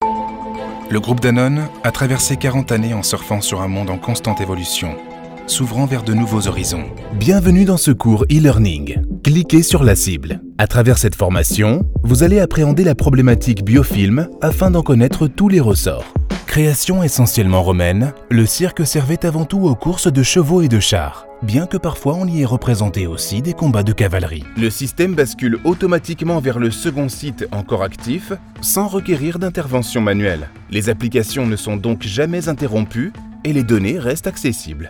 Commercieel, Natuurlijk, Veelzijdig, Vriendelijk, Zakelijk
Corporate